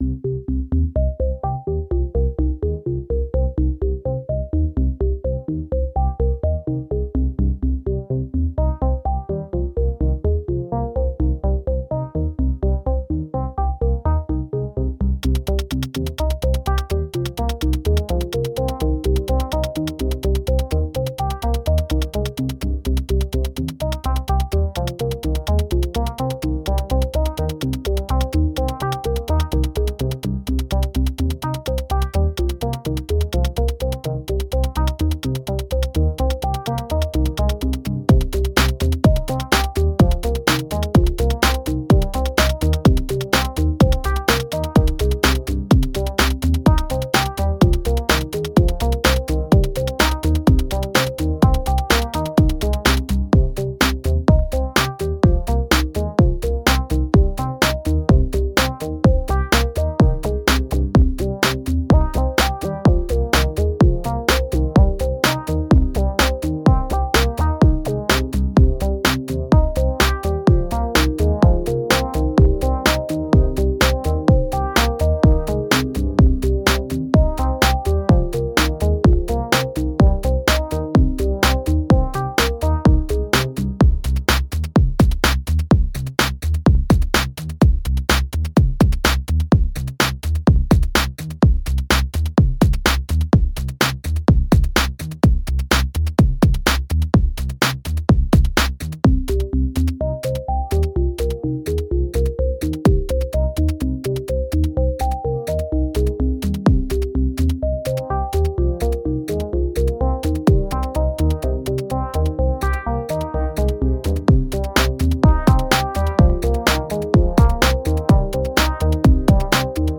At the heart of my setup is my beloved MPC one, which I use as a sequencer, mixer, drum computer and FX processor. I have some KORG Volcas which I occasionally use, and an Arturia MicroFreak as my main synth. A trusty ZOOM H1 serves as my audio interface over which I record on my computer (Lenovo Think Pad T430 running Debian).